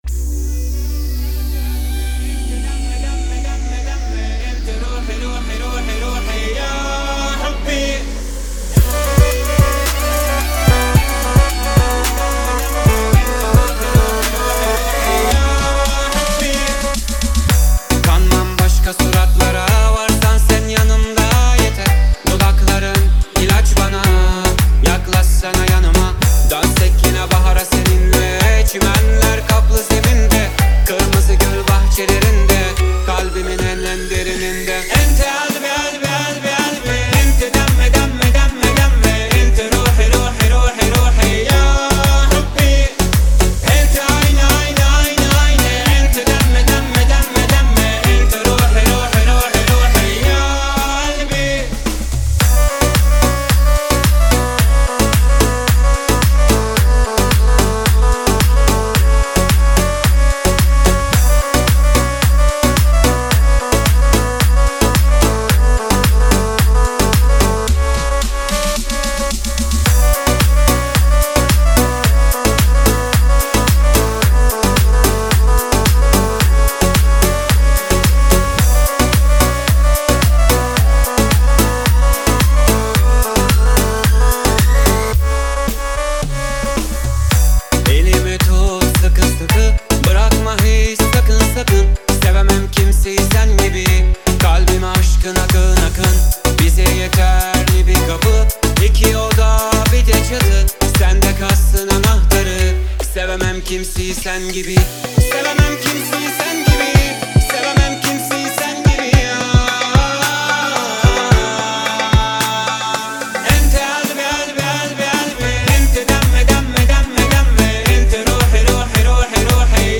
remix-chalesh-instagram-bastani.mp3